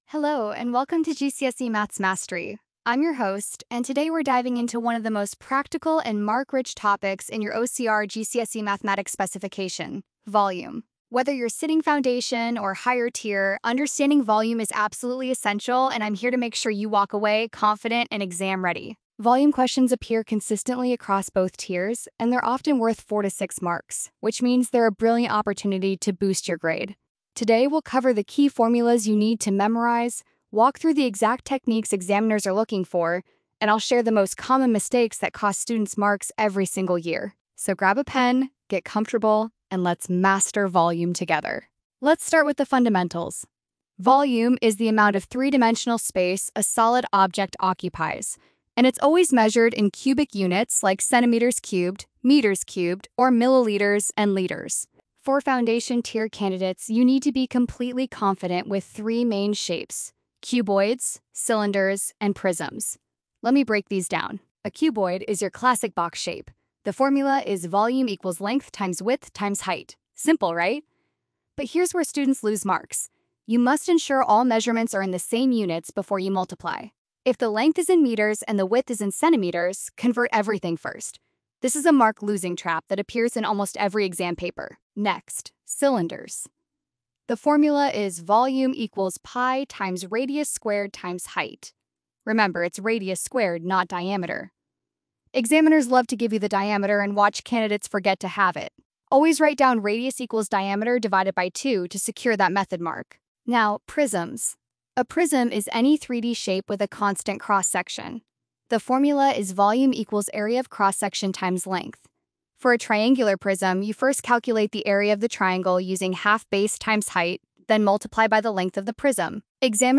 Listen to our expert tutor break down the key concepts, exam techniques, and common mistakes for Volume.